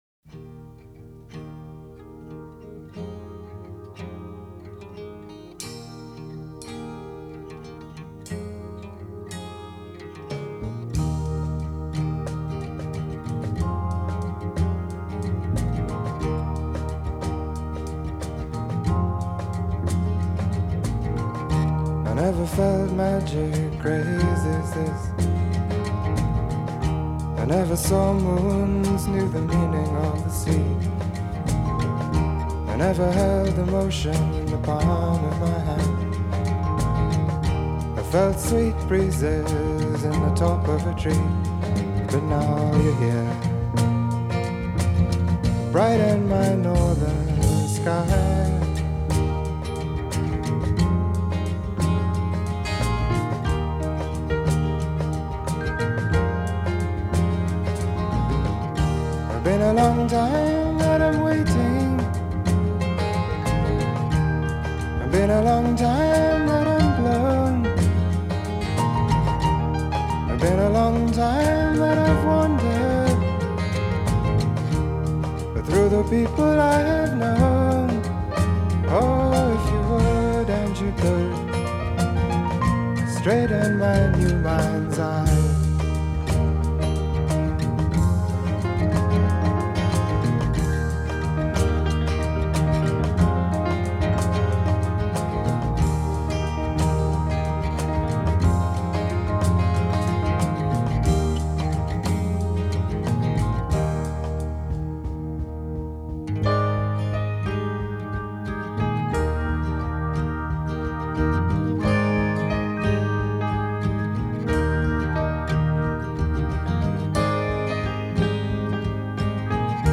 Жанр: Pop, Folk